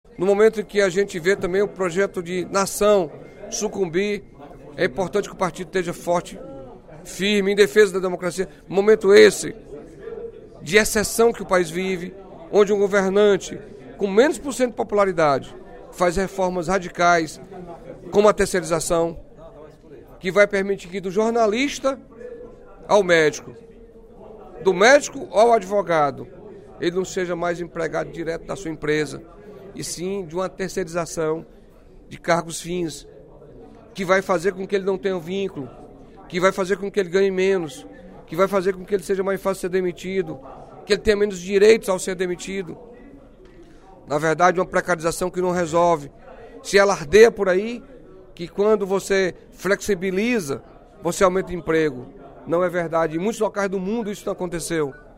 O deputado Carlos Felipe (PCdoB) informou, durante o primeiro expediente da sessão plenária desta quinta-feira (23/03), que o aniversário de 95 anos de fundação do Partido Comunista do Brasil (PCdoB) será celebrado neste sábado (25/03).